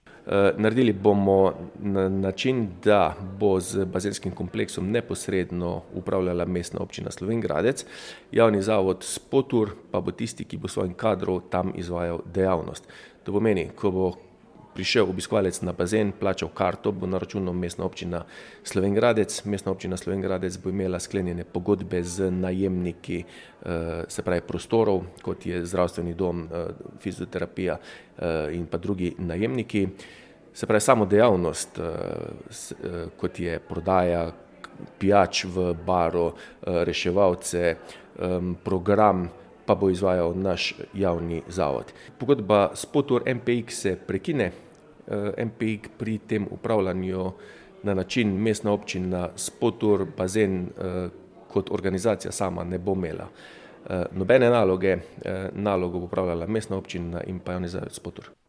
Več pa župan Tilen Klugler.